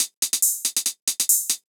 Index of /musicradar/ultimate-hihat-samples/140bpm
UHH_ElectroHatB_140-04.wav